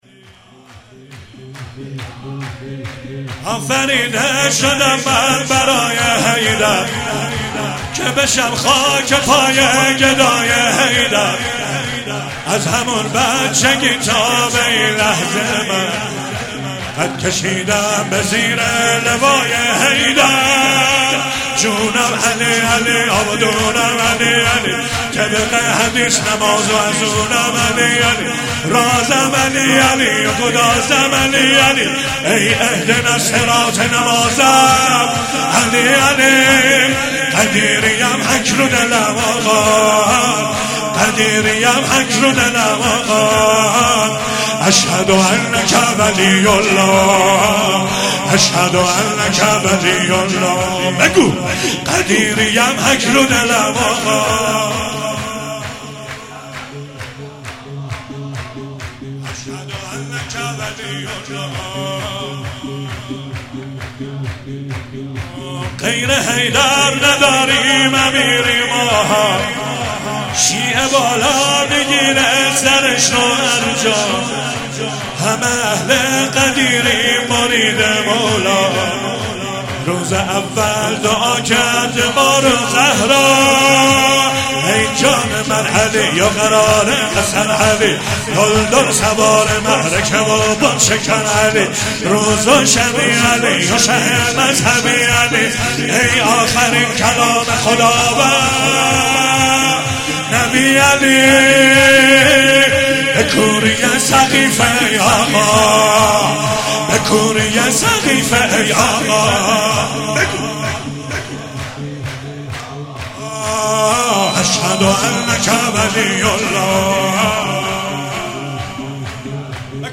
هفتگی 23 اسفند - شور - آفریده شـدم من برای حیـدر